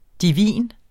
Udtale [ diˈviˀn ]